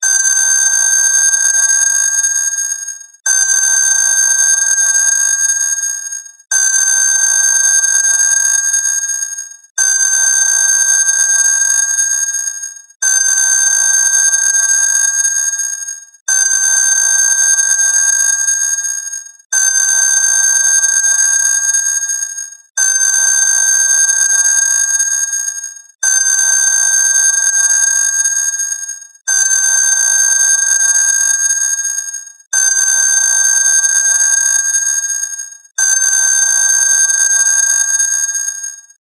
まるで昔ながらのアナログ時計が鳴らすような目覚まし音です。その音色は、じりりりり...！と独特なリズムで響き渡ります。
最初に耳に届くのは、時計の歯車がゆっくりと動く音。じりりりり...という響きは、まるで時の流れを感じさせます。